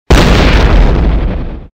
b_baozha3.mp3